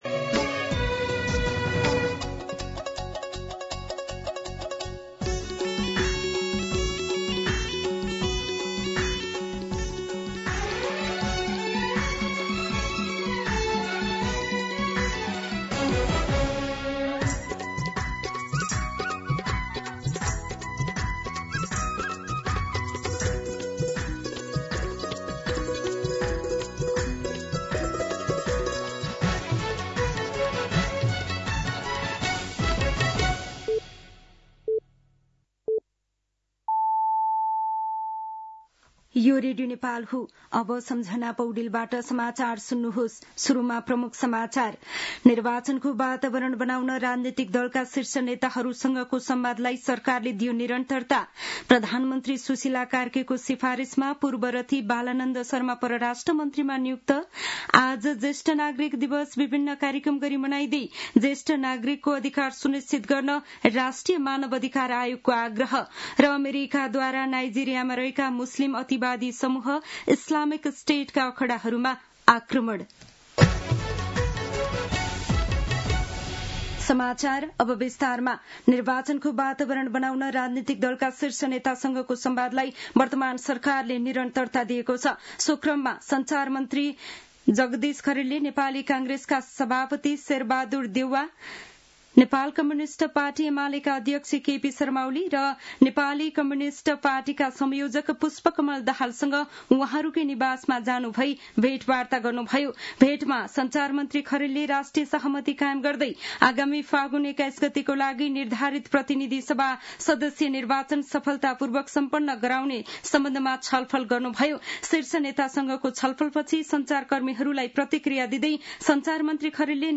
दिउँसो ३ बजेको नेपाली समाचार : ११ पुष , २०८२